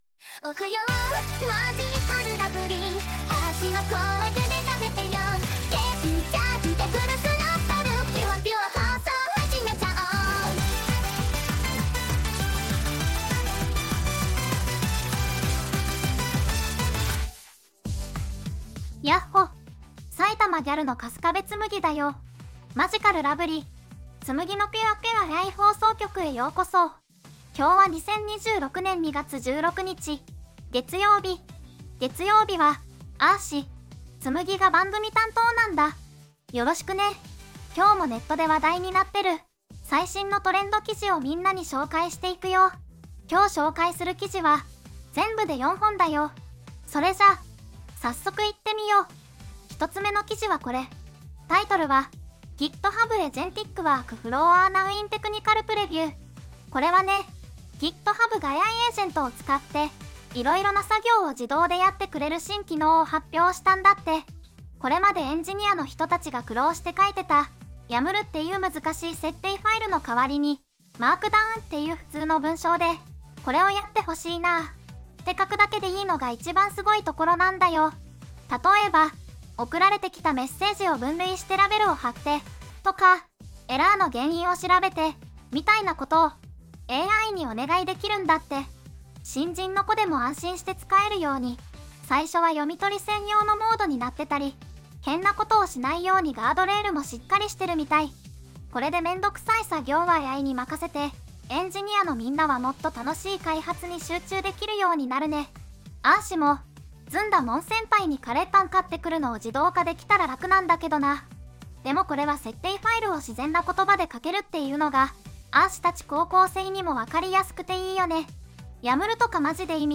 VOICEVOX:春日部つむぎ